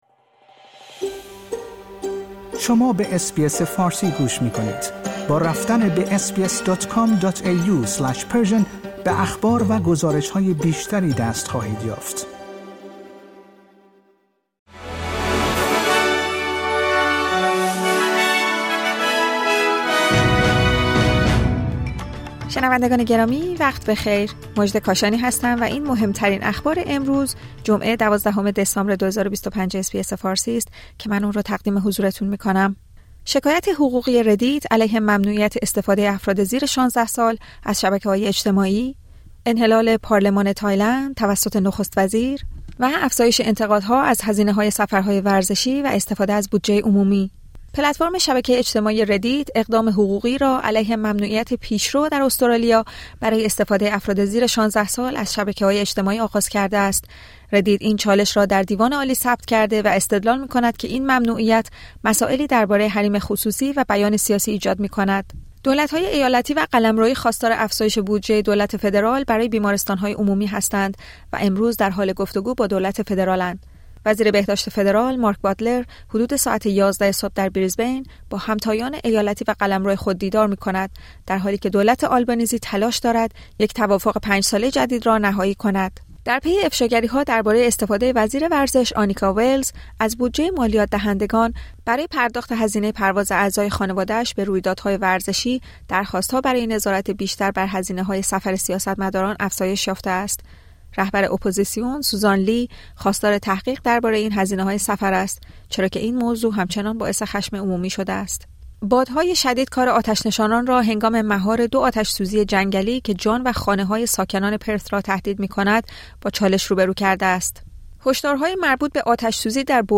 در این پادکست خبری مهمترین اخبار روز جمعه ۱۲ دسامبر ارائه شده است.